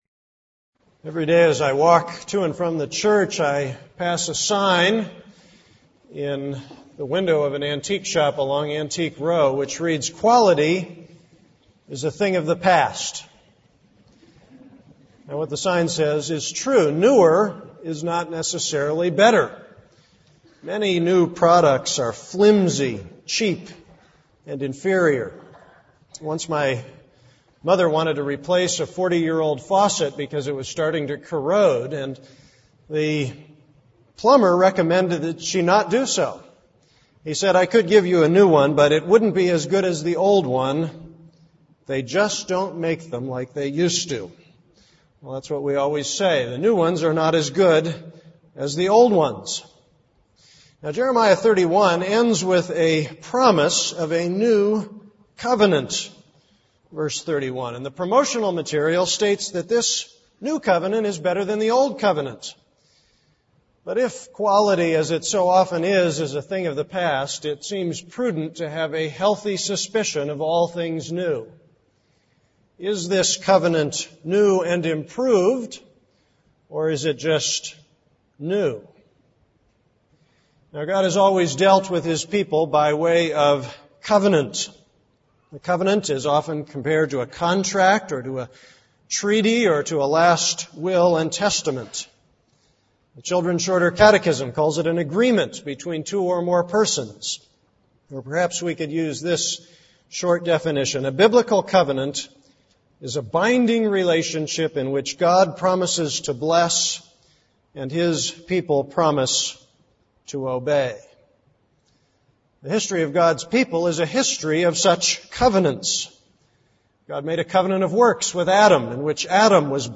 This is a sermon on Jeremiah 31:27-40.